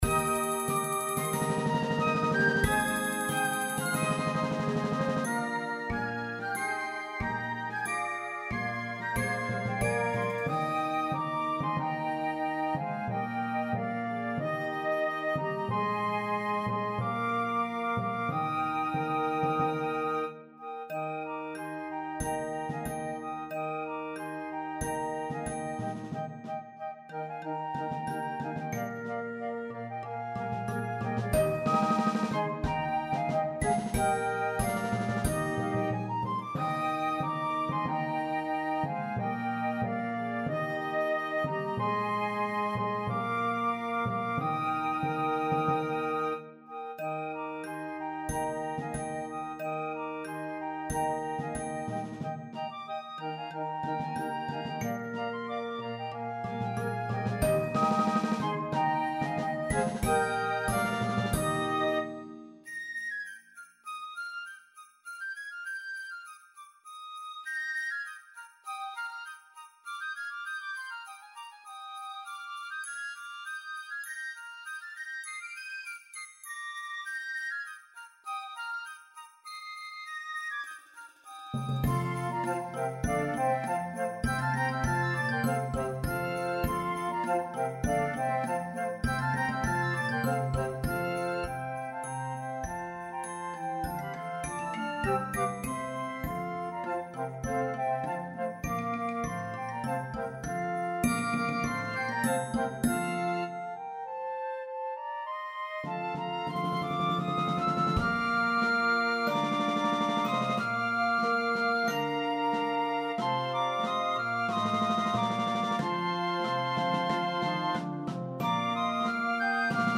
C- und C/B-Besetzung MIT Alt- und Bassflöten
Flötenorchester